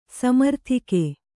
♪ samarthike